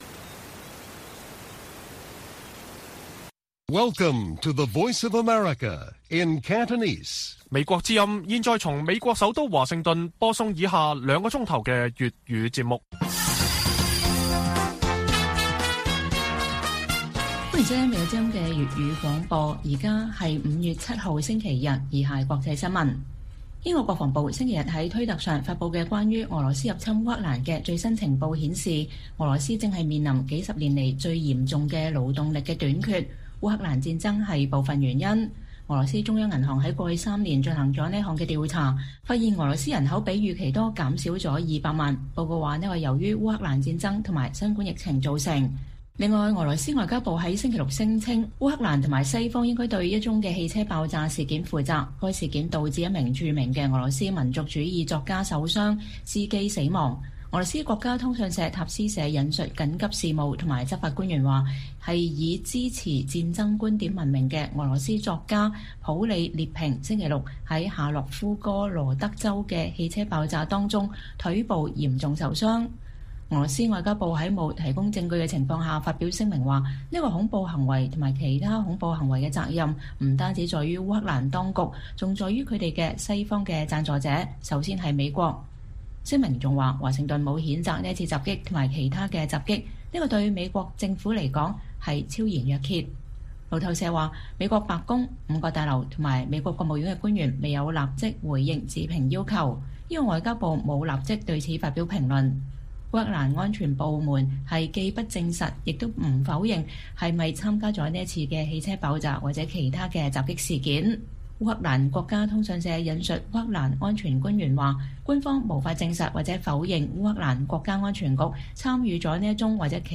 粵語新聞 晚上9-10點: 英國稱烏克蘭戰爭導致俄羅斯勞動力短缺